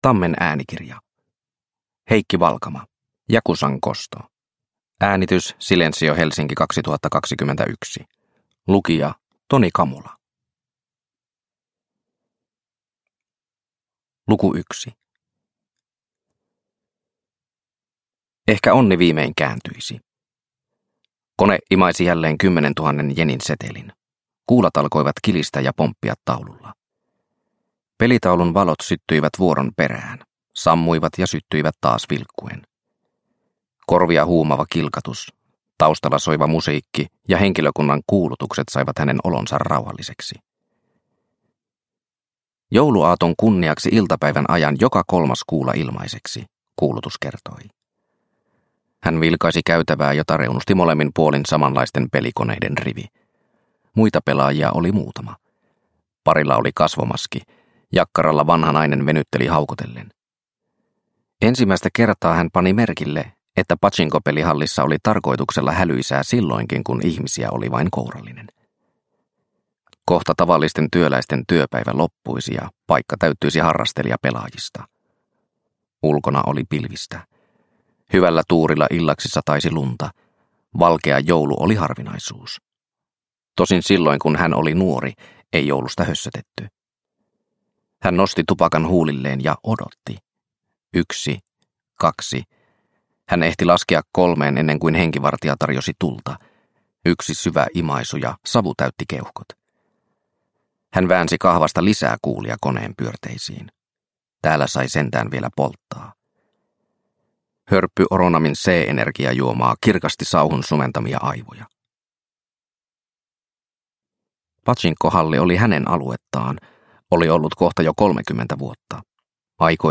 Yakuzan kosto – Ljudbok – Laddas ner